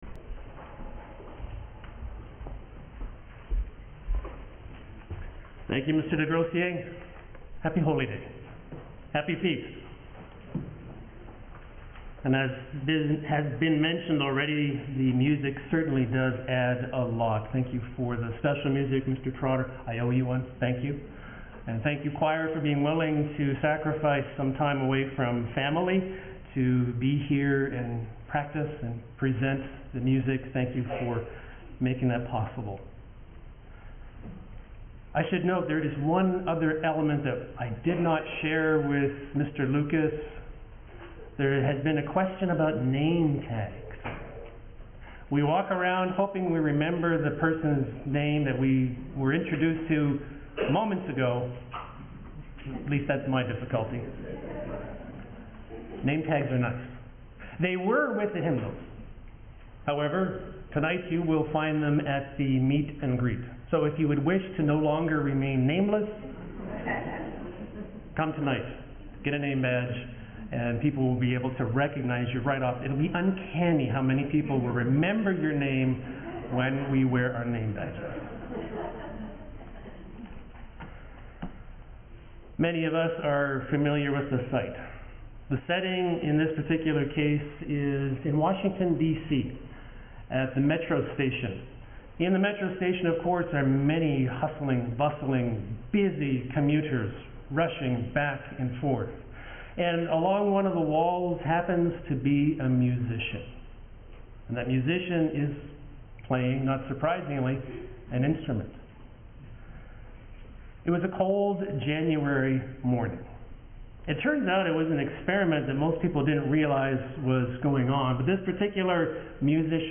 This sermon was given at the Midland, Ontario 2024 Feast site.